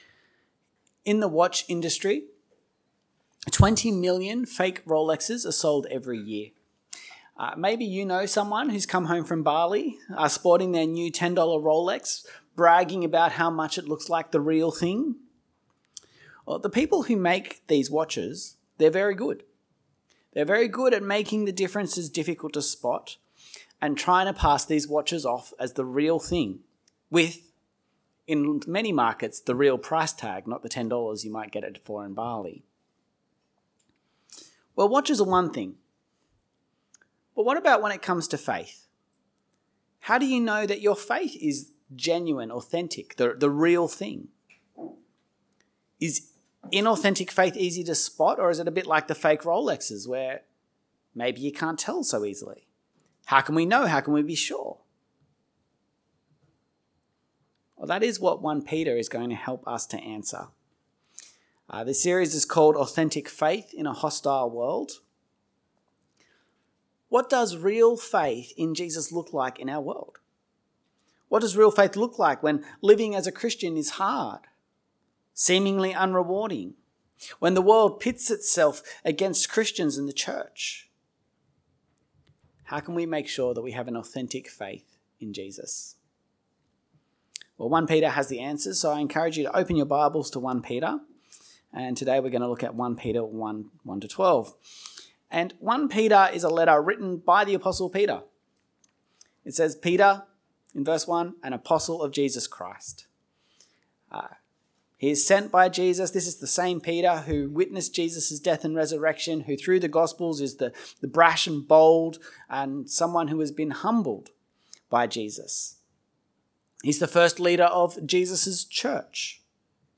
Sermon Series - St. James Anglican Church Kununurra